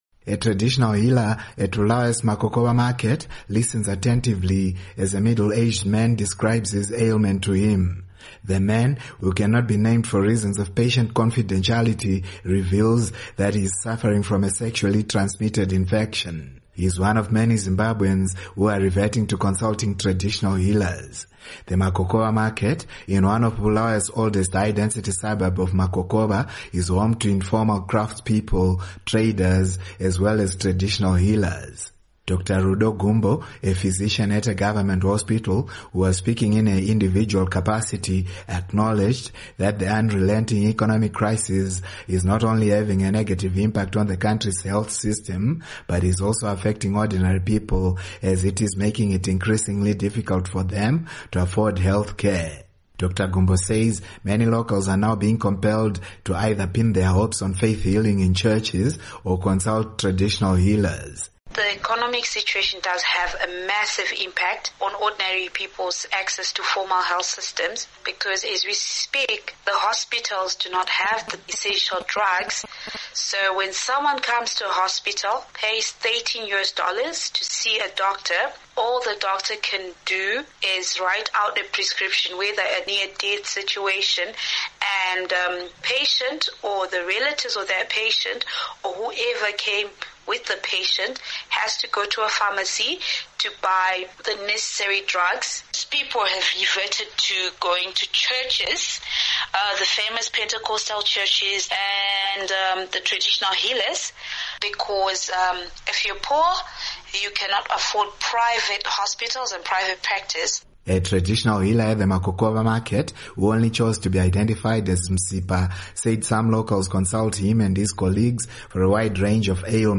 Report on Alternative Health